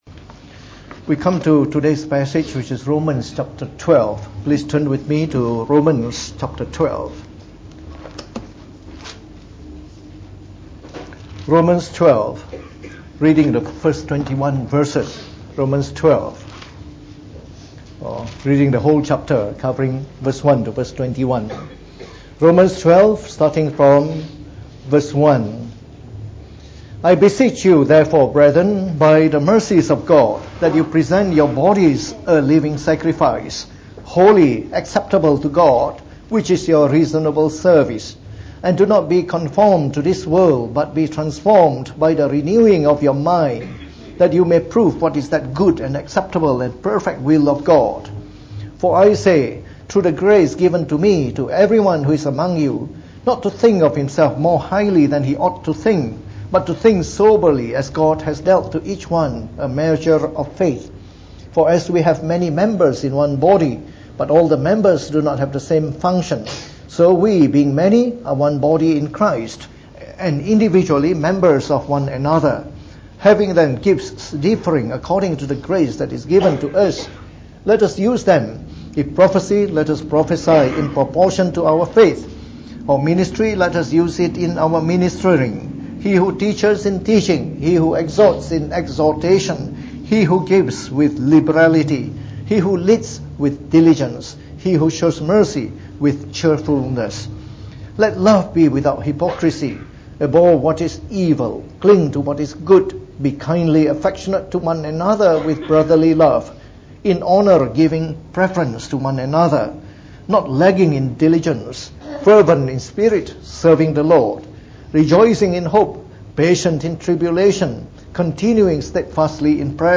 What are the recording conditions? Preached on the 30th of September 2018.